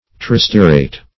Tristearate \Tri*ste"a*rate\, n.